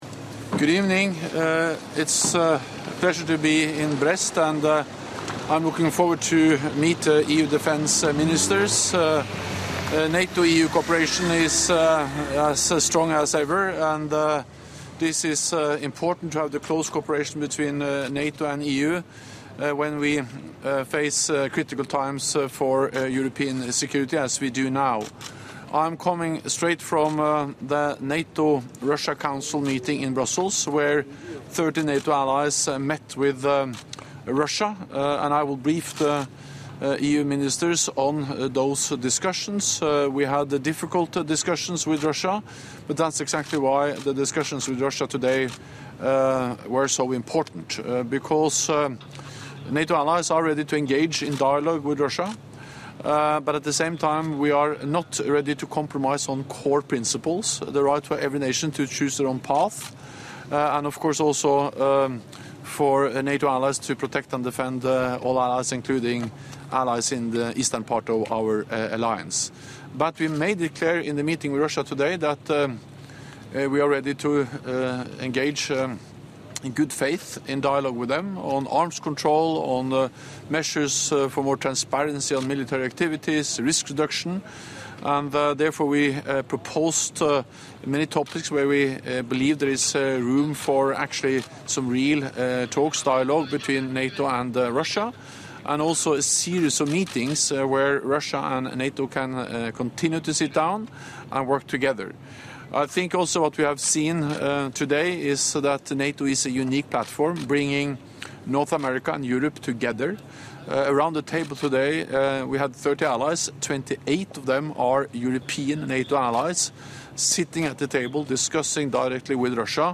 Doorstep statement
by NATO Secretary General Jens Stoltenberg following the meeting of the NATO-Russia Council